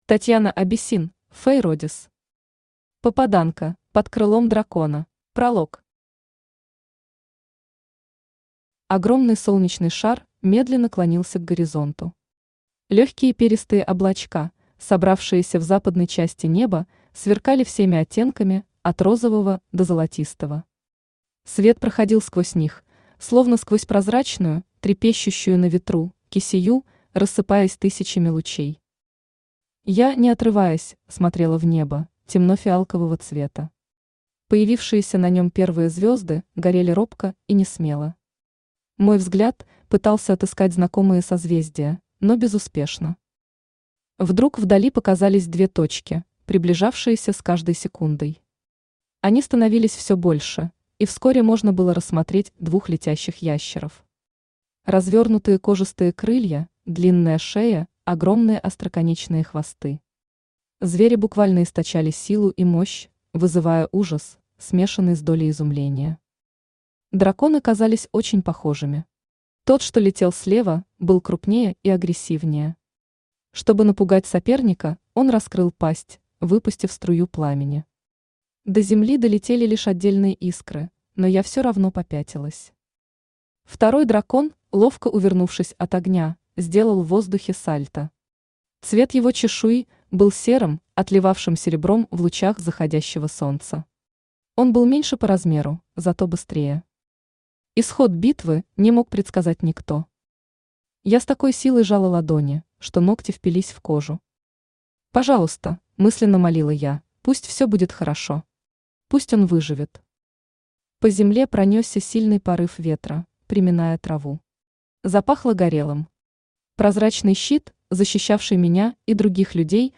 Aудиокнига Попаданка: под крылом дракона Автор Татьяна Абиссин Читает аудиокнигу Авточтец ЛитРес.